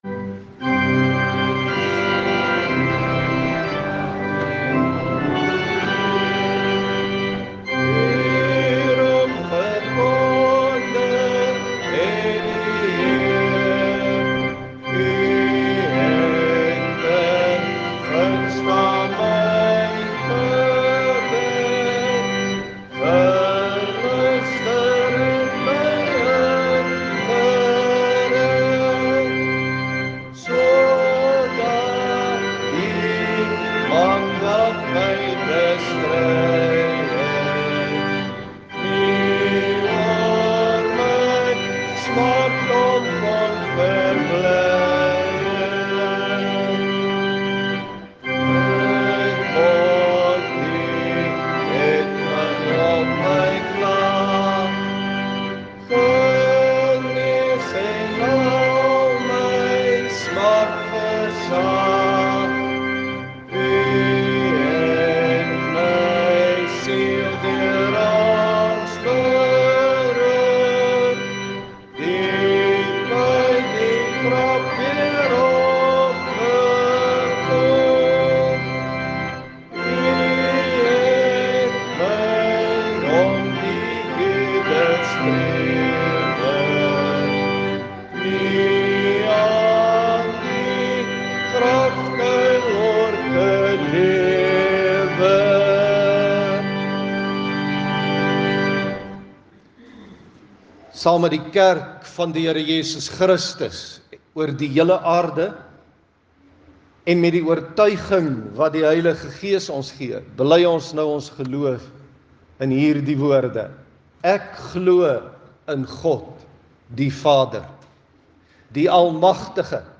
Klankbaan Luister na die preek.